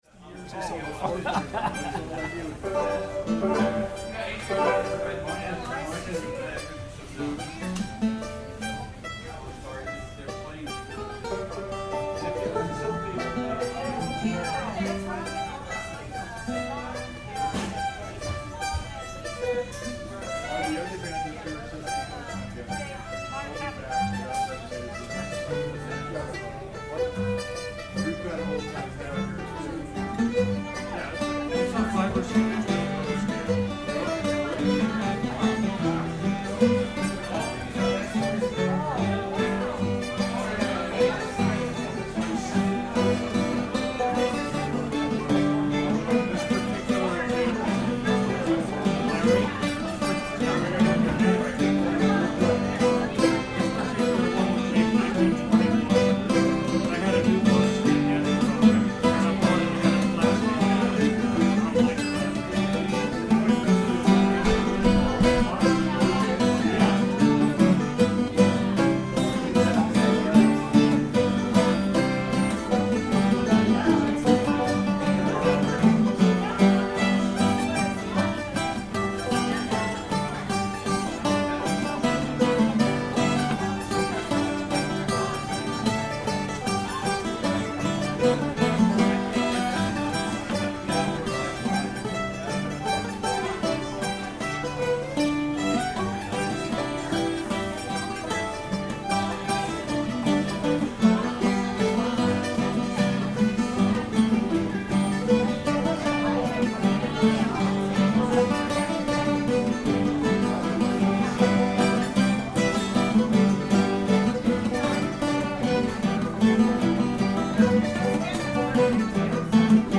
kansas city rag [G]